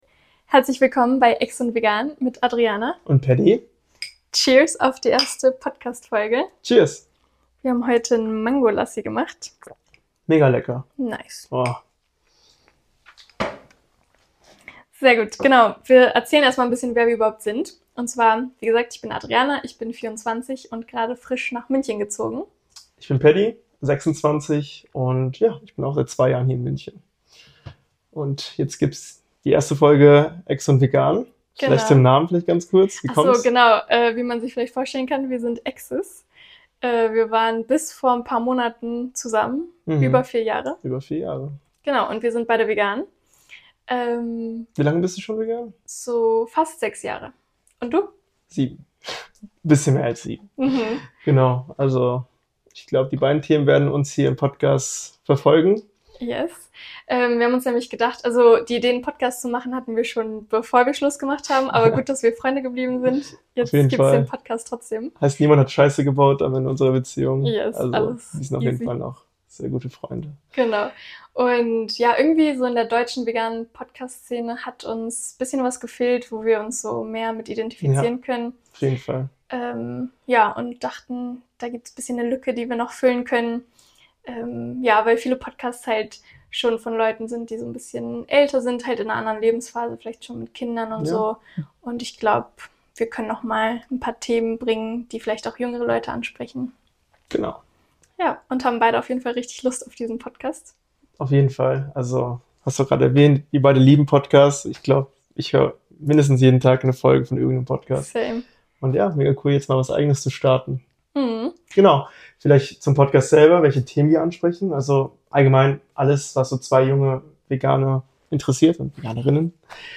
Übrigens: Ab Folge 3 wird die Audioqualität deutlich besser und danach auch bald die Videoqualität.